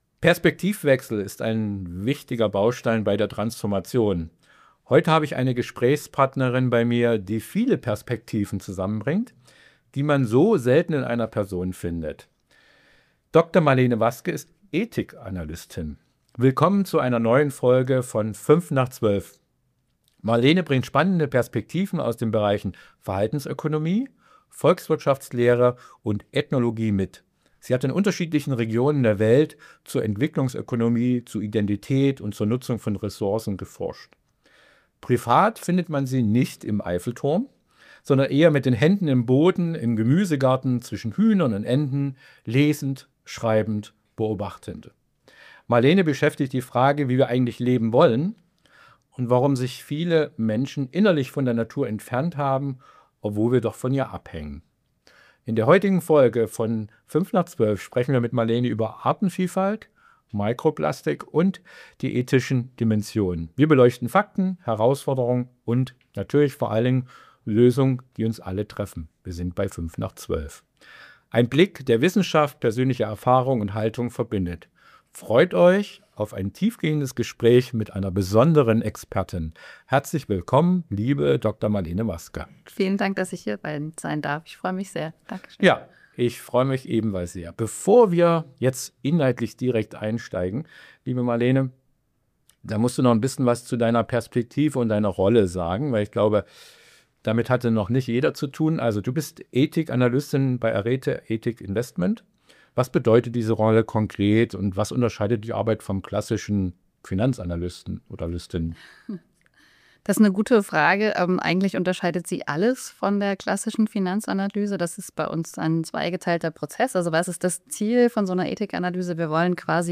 Ein Gespräch über Ethik, Verantwortung und die Chance, Wandel zu gestalten, bevor es zu spät ist.